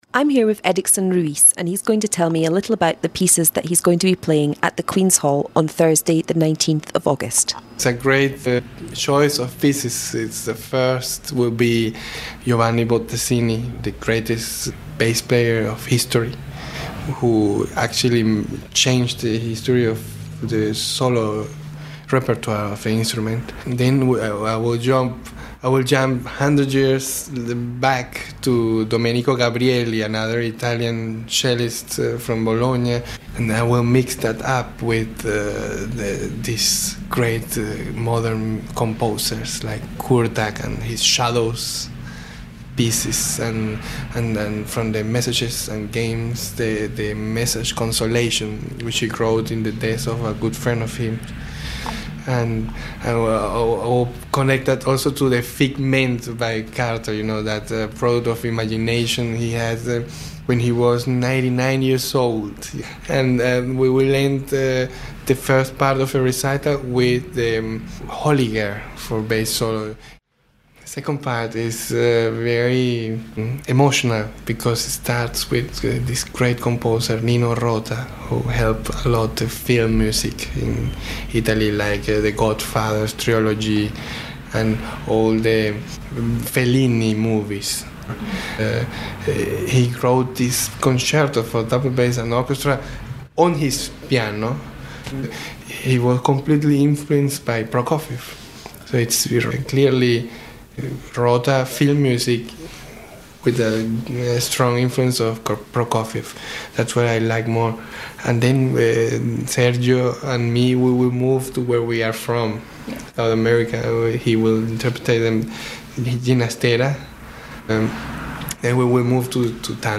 Interview with Edicson Ruiz